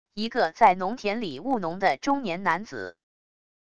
一个在农田里务农的中年男子wav音频